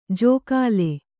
♪ jōkāle